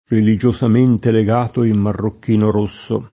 reliJoSam%nte leg#to im marrokk&no r1SSo] (R. Ridolfi) — sim. i cogn. Marocchini, Marrocchini